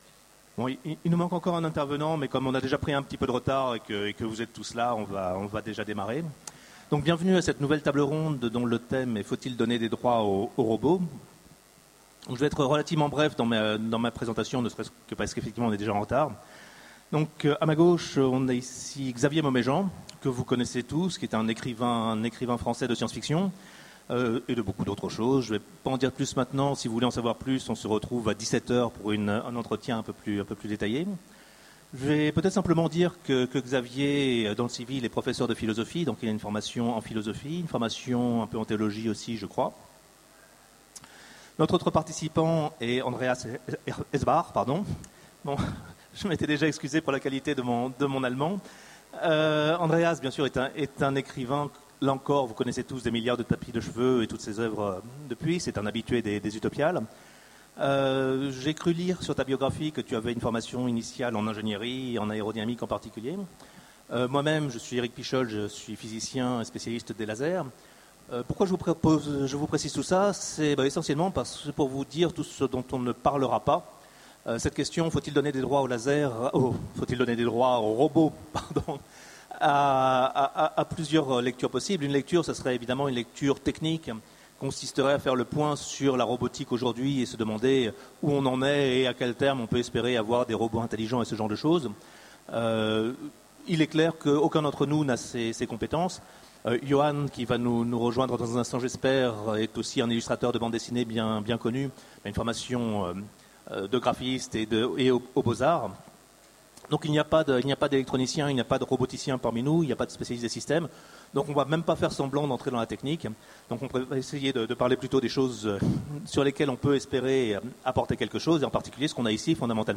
Utopiales 13 : Conférence Faut-il donner des droits aux robots ?